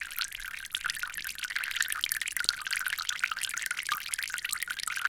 bathroom-sink-03
bath bathroom bubble burp click drain dribble dripping sound effect free sound royalty free Sound Effects